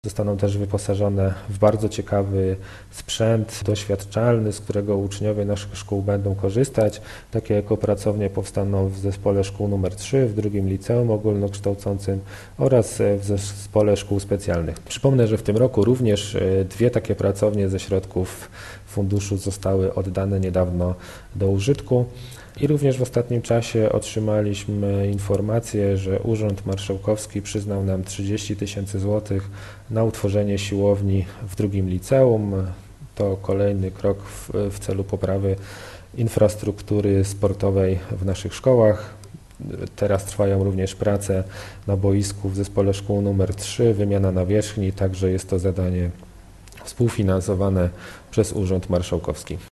Zostaną też wyposażone w bardzo ciekawy sprzęt doświadczalny, z którego uczniowie naszych szkół będą korzystać – mówi wicestarosta wieluński, Krzysztof Dziuba.